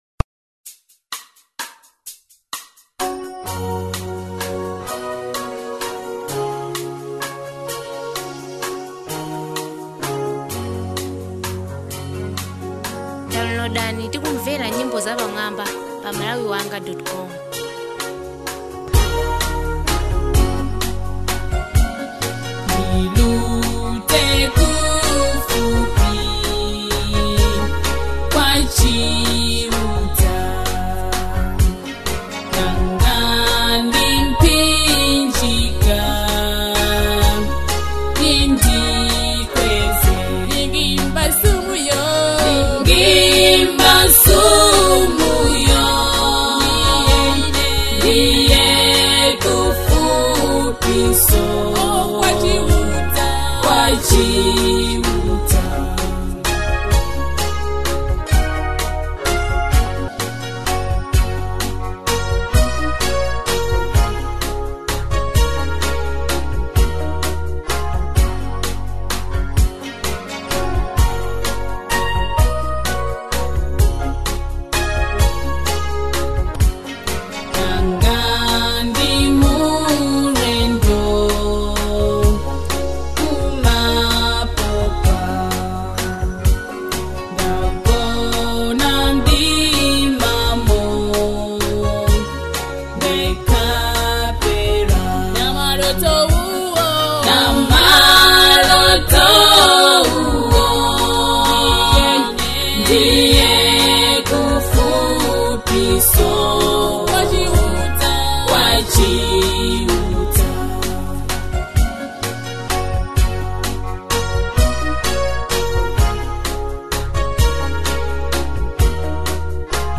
Malawian Upcoming Female Gospel Singer (Worshiper)